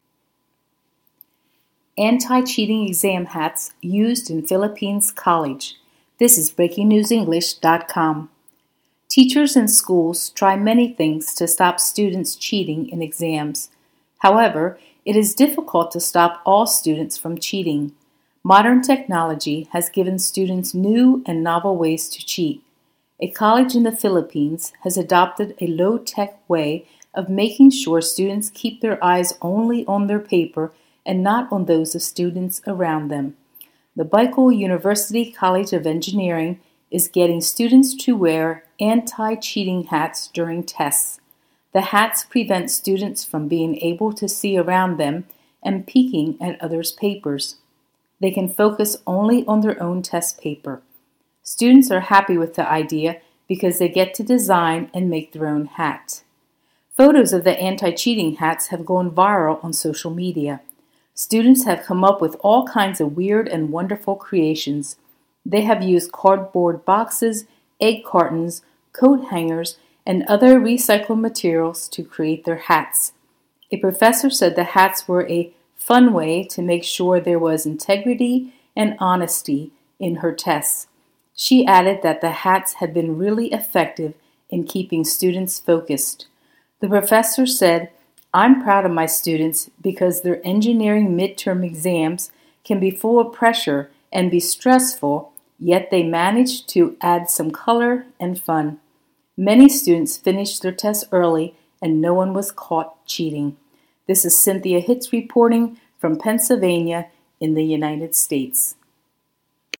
AUDIO (Normal)